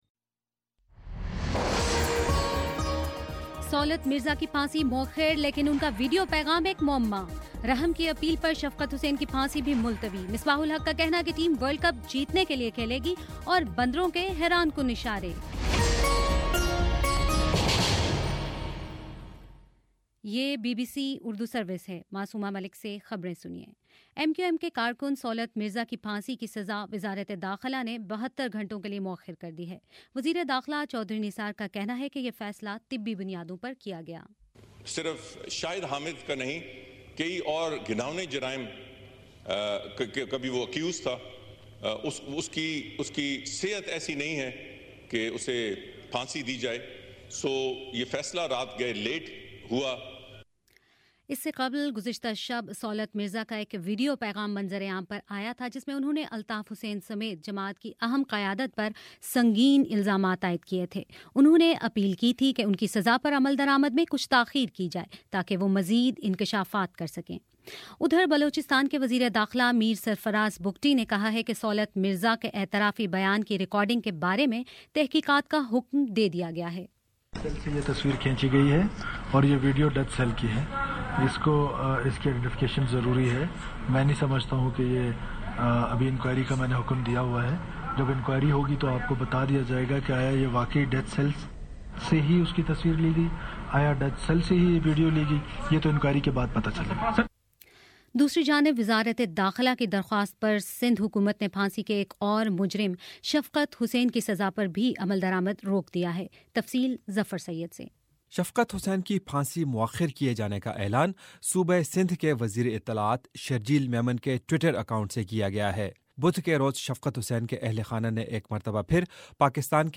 مارچ 19: شام چھ بجے کا نیوز بُلیٹن